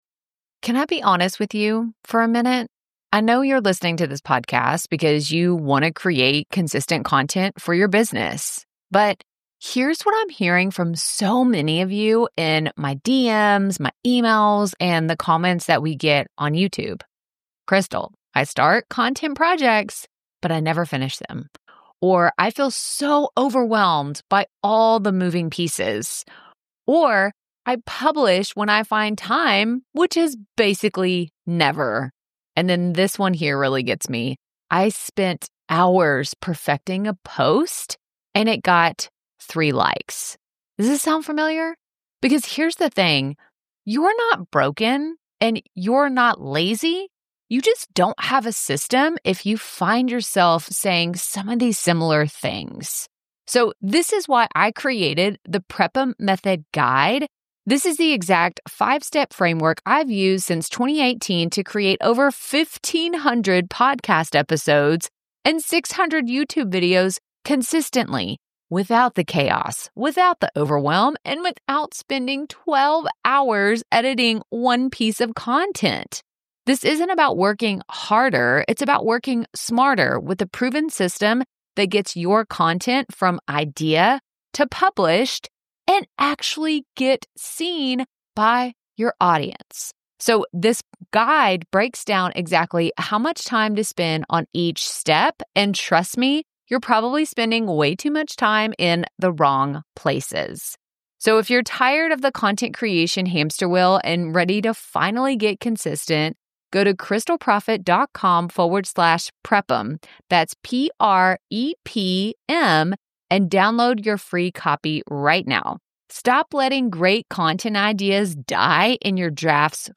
Today, I'm walking and talking about the universal fear that plagues nearly every content creator: publishing your first piece .